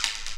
synTTE55015shortsyn-A.wav